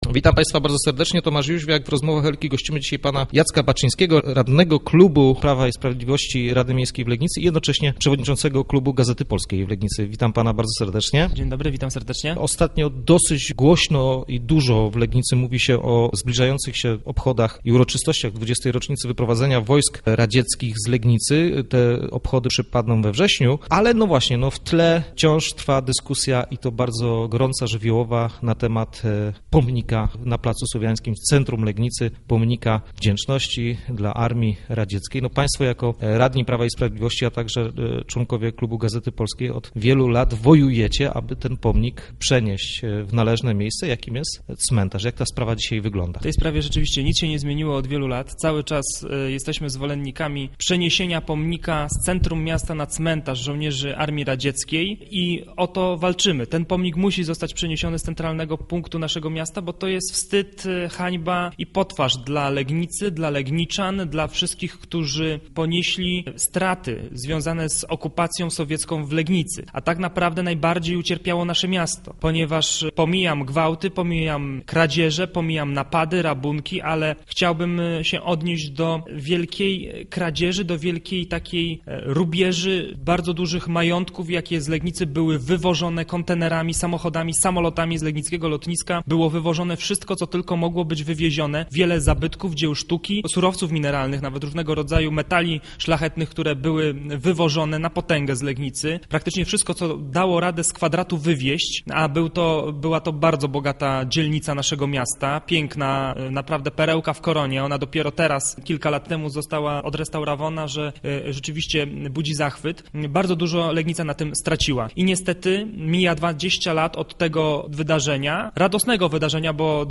Legniccy radni Prawa i Sprawiedliwości domagają się usunięcia z centrum miasta pomnika Wdzięczności dla Armii Radzieckiej, pieszczotliwie zwanego przez mieszkańców "pomnikiem dwóch Iwanów". Naszym gościem był radny klubu PiS, Jacek Baczyński.